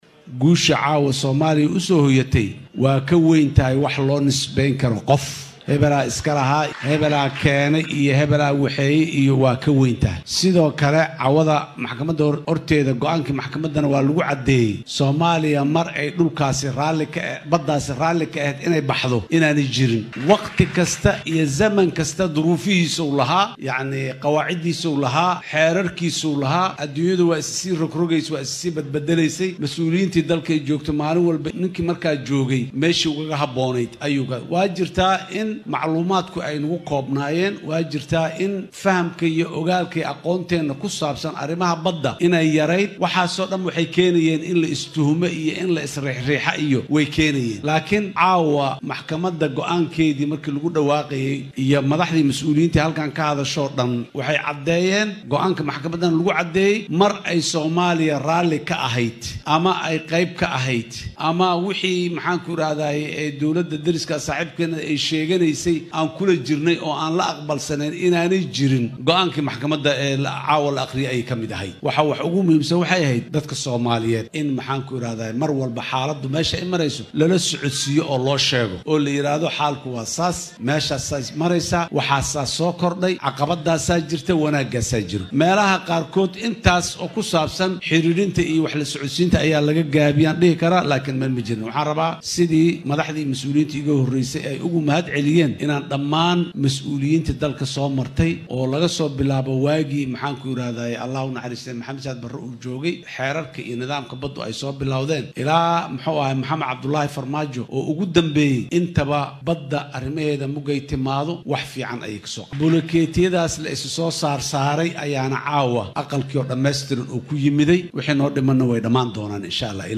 Madaxweynihii hore ee Soomaaliya Xasan Sheekh Maxamuud oo xalay ka qayb galay xaflad weyn oo Muqdisho lagu qabtay looguna dabaal dagayay go’aanka maxkamadda ayaa sheegay in guud ahaan dadka Soomaaliyeed ay ka midaysnaayeen arrinta kiiska Badda.